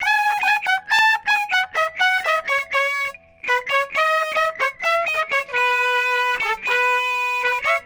FsharpMelody.wav